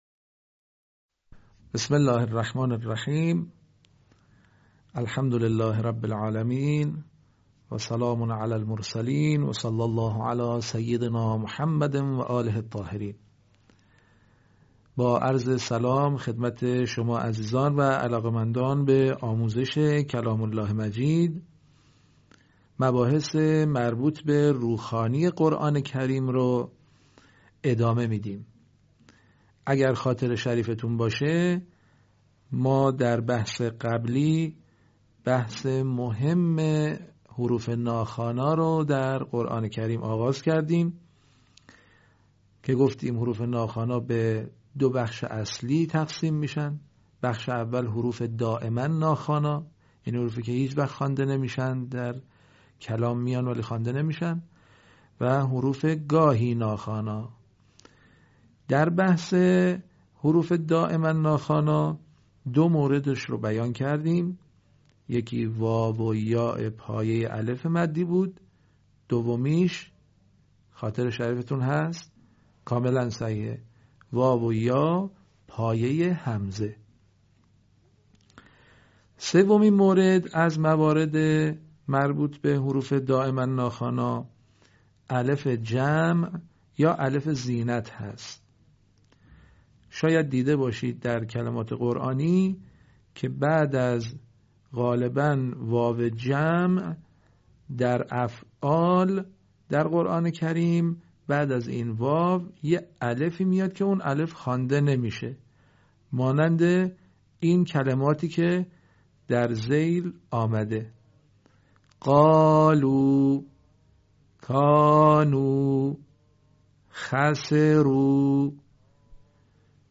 صوت | آموزش «الف جمع یا زینت» در روخوانی قرآن کریم